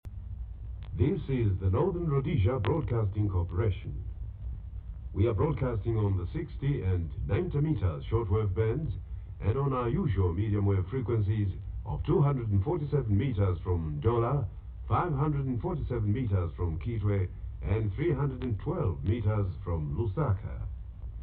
Station ID Audio